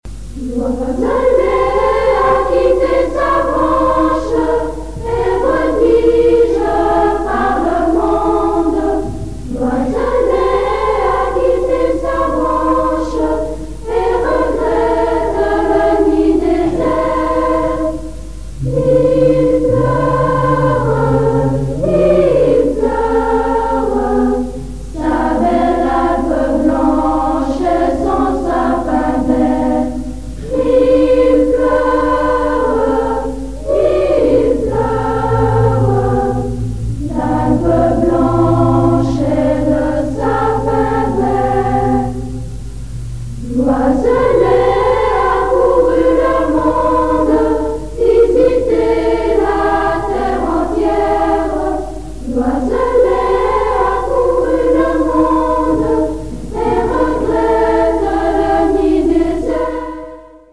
1985 - 1986 - Choeur d'enfants La Voix du Gibloux